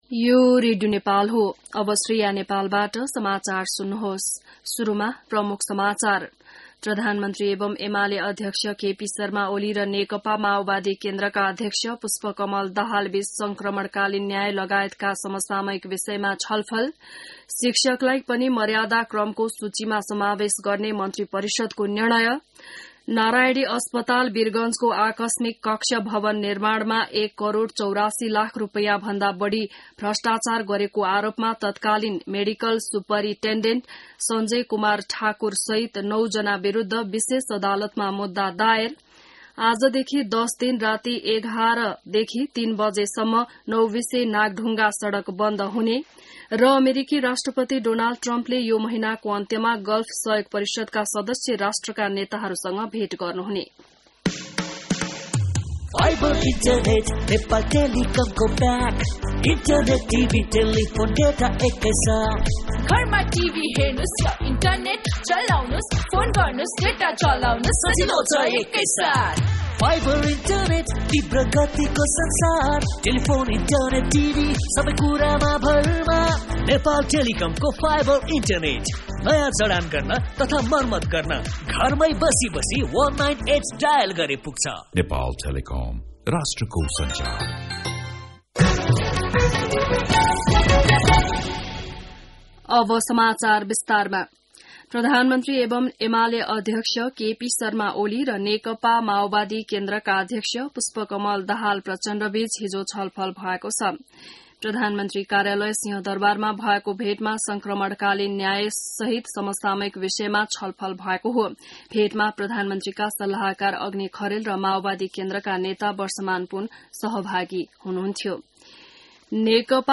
बिहान ७ बजेको नेपाली समाचार : २२ वैशाख , २०८२